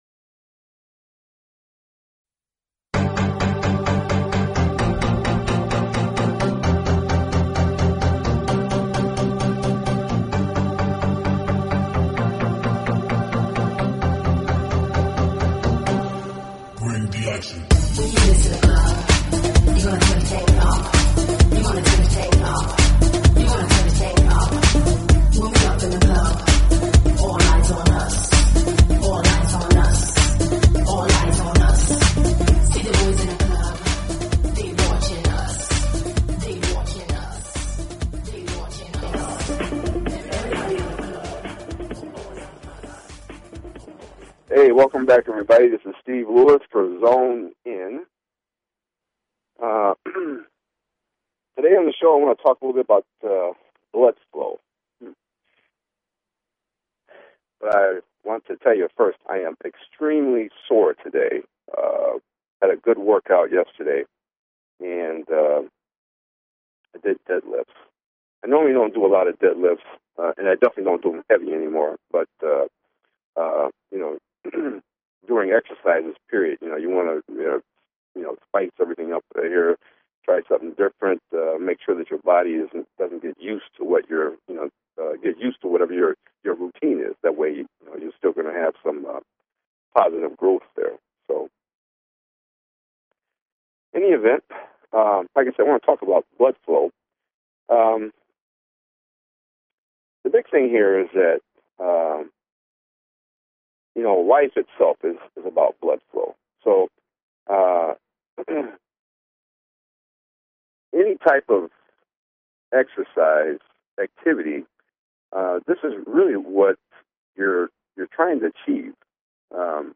Talk Show Episode, Audio Podcast, Zone In!